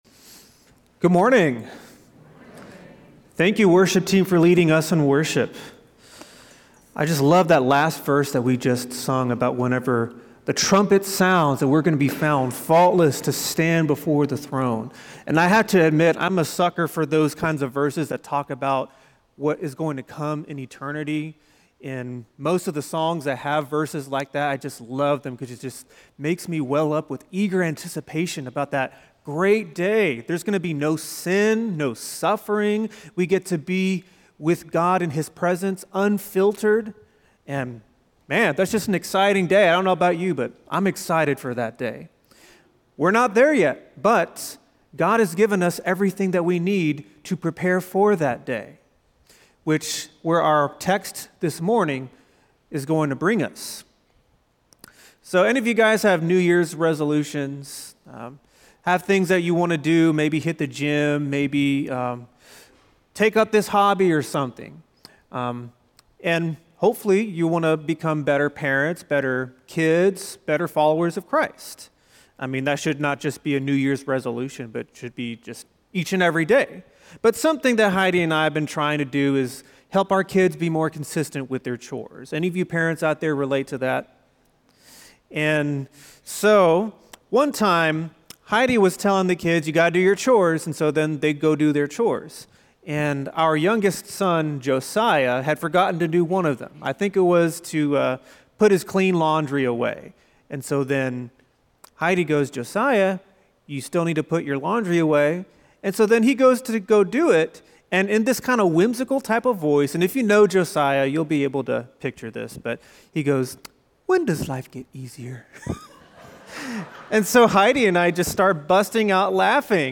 Cary Alliance Church sermons